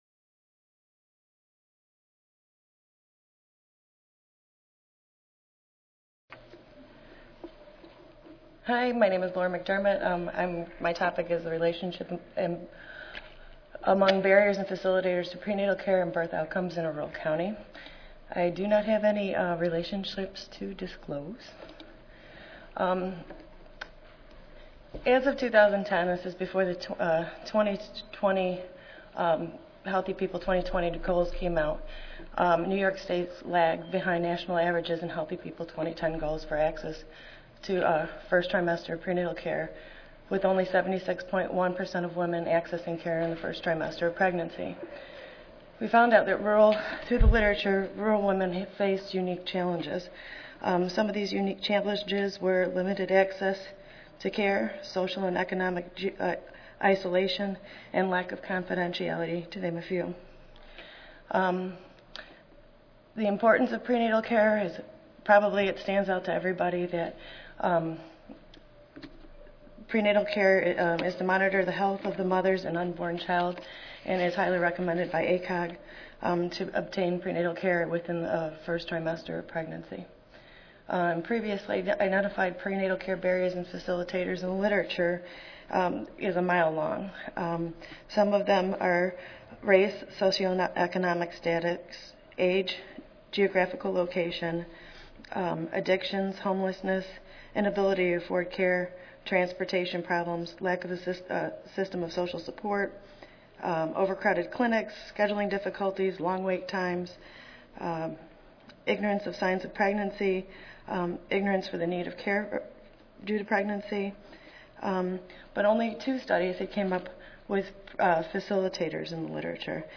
4336.0 Social Epidemiology & social determinants for maternal health: Implications for public policy Tuesday, November 1, 2011: 2:30 PM Oral This session focuses on social epidemiology and social determinants for maternal health and their implications for public policy.